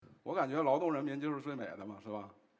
spk1___10-7___reverb_clean.wav